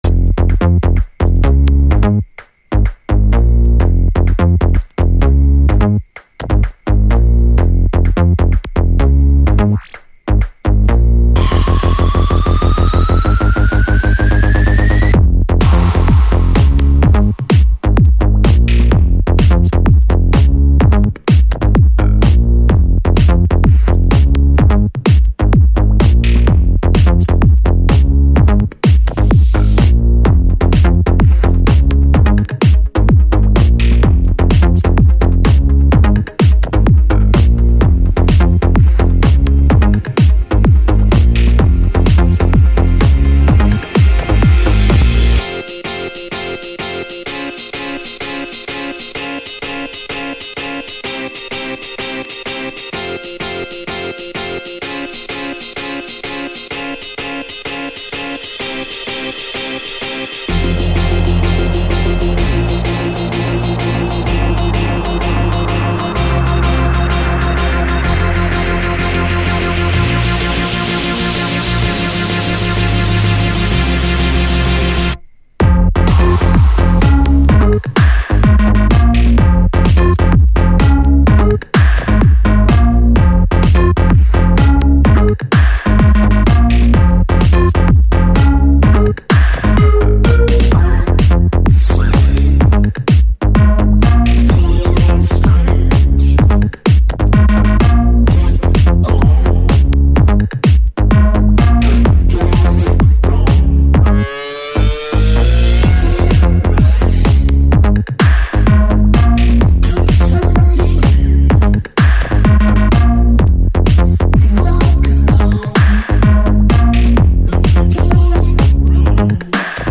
Категория: Клубняк